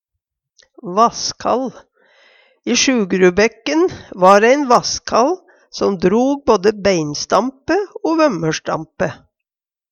vasskall - Numedalsmål (en-US)
Hør på dette ordet Ordklasse: Substantiv hankjønn Kategori: Vêr og føre Reiskap og arbeidsutstyr Tekstilhandverk Attende til søk